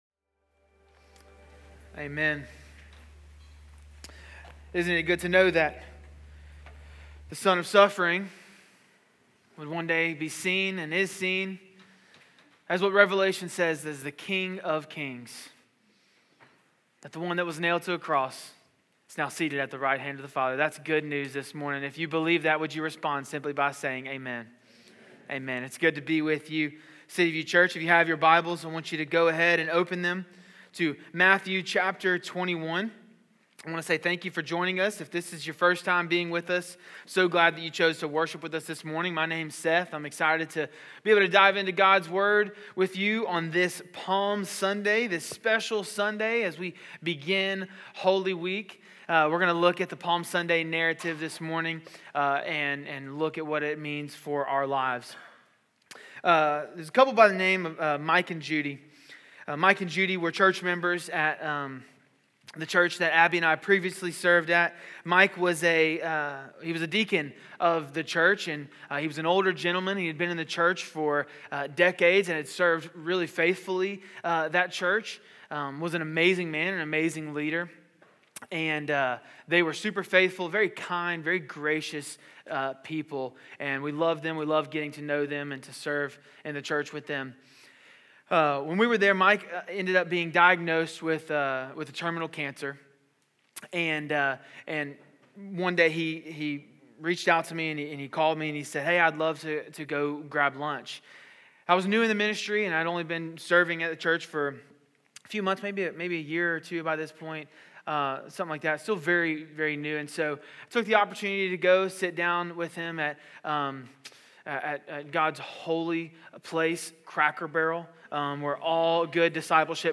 City View Church - Sermons Who is this?